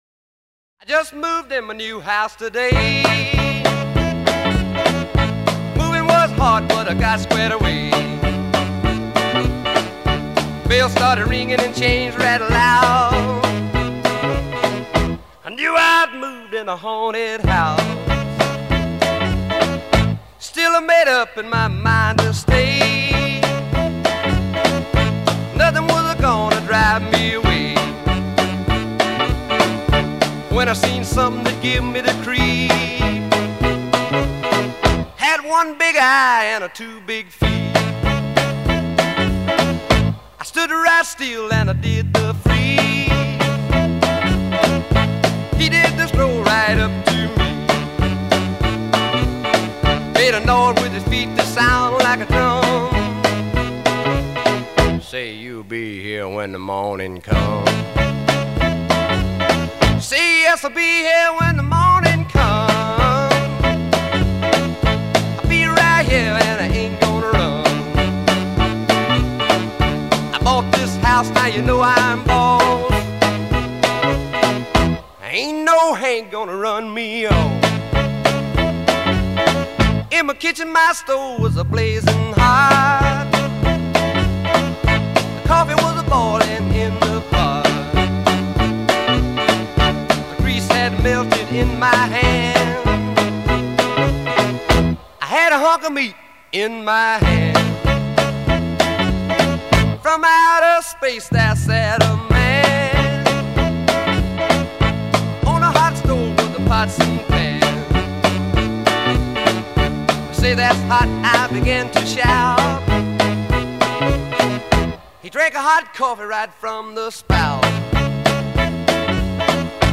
rockabilly